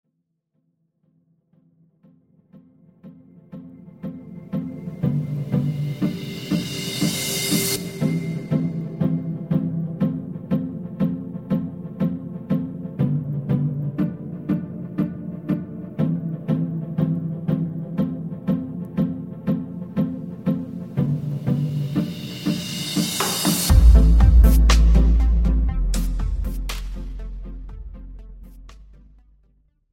This is an instrumental backing track cover.
• No Fade